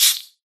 mob / silverfish / hit2.ogg
hit2.ogg